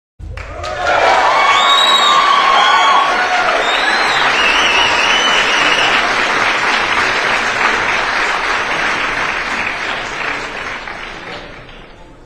دانلود آهنگ دست و سوت و شادی مردم از افکت صوتی انسان و موجودات زنده
دانلود صدای دست و سوت و شادی مردم از ساعد نیوز با لینک مستقیم و کیفیت بالا
جلوه های صوتی